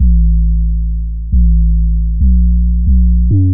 疯狂的Trap Sub Bass
描述：疯狂的陷落式低音炮 136
Tag: 136 bpm Trap Loops Bass Loops 611.21 KB wav Key : Unknown